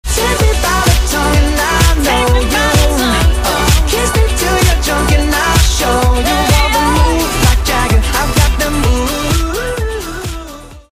GenrePop/Rock